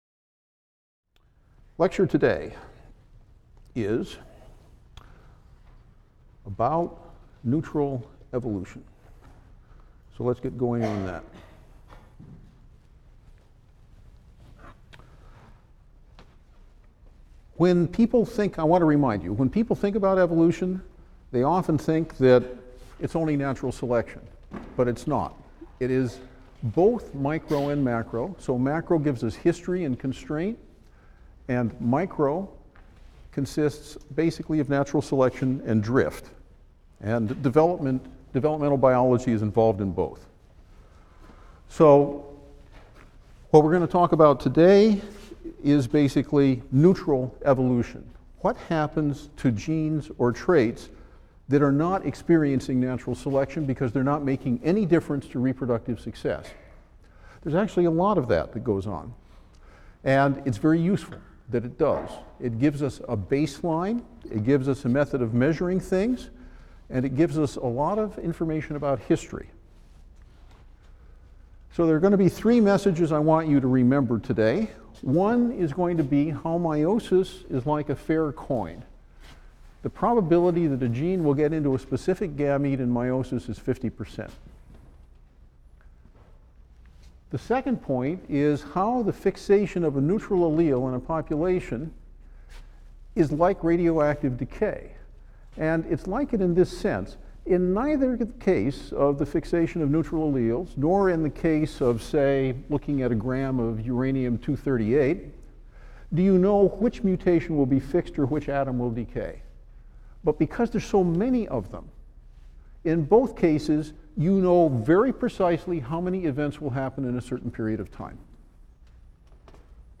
E&EB 122 - Lecture 4 - Neutral Evolution: Genetic Drift | Open Yale Courses